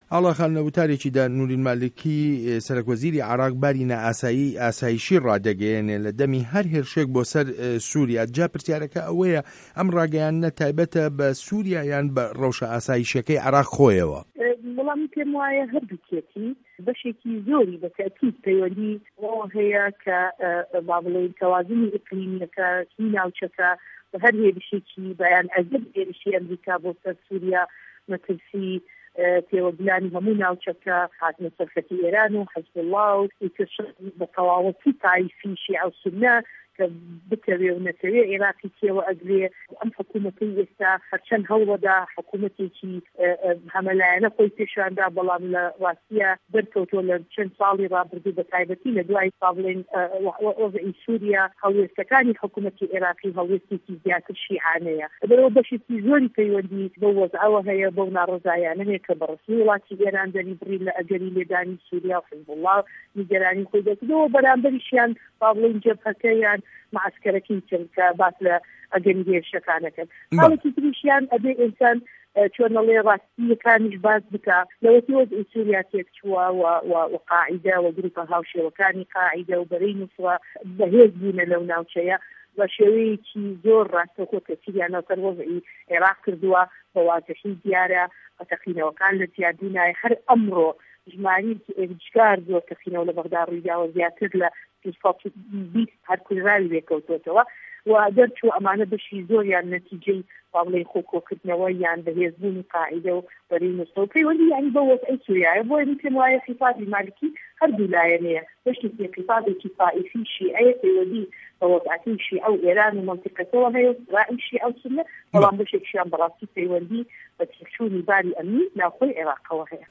وتووێژ له‌گه‌ڵ ئاڵا تاڵه‌بانی